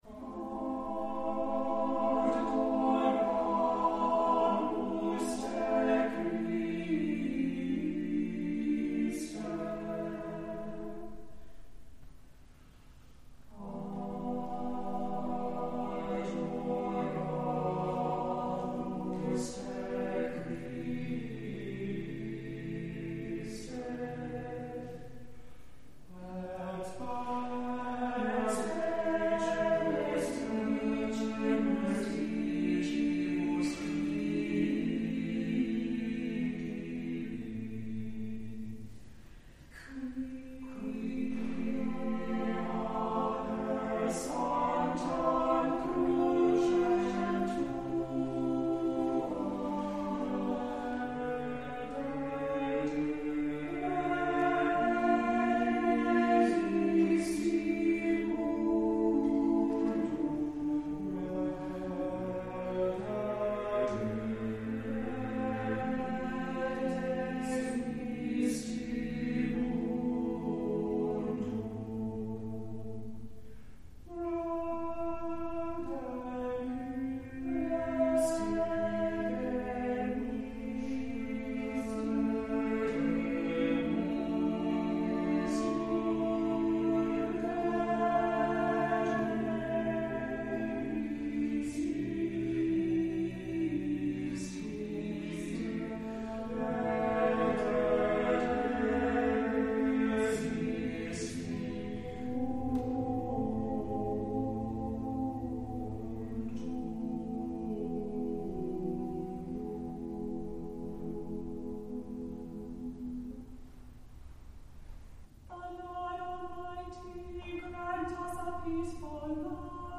On Sundays, virtual and in-person services of prayers, scripture, and a sermon are at 8 a.m., 10:30 a.m., and 6 p.m., and a sung service of Compline begins at 7 p.m.
Christ Church Audio Home Categories Admin Compline 2025-03-09 The Compline Choir Download Size: 9 MB 1 Powered by Podcast Generator , an open source podcast publishing solution | Theme based on Bootstrap